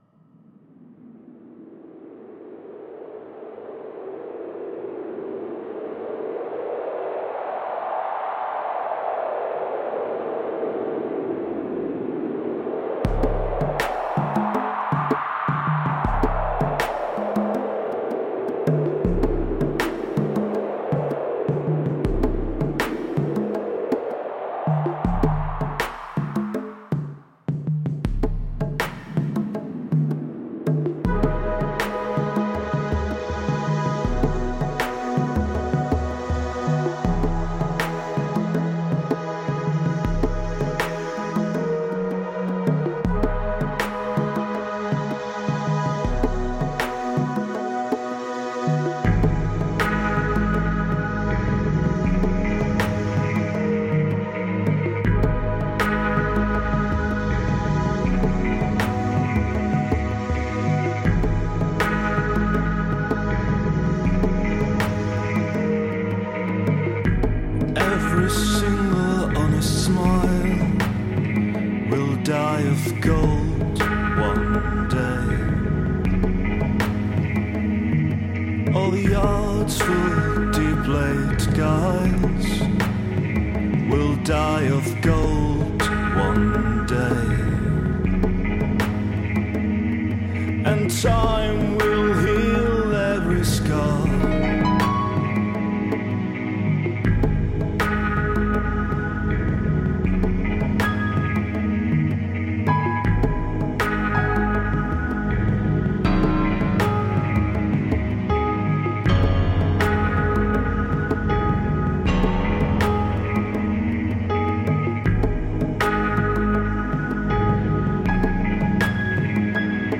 Žánr: Indie/Alternativa
Nahráno leden až květen 2016, Praha & Beroun